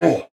ogre5.wav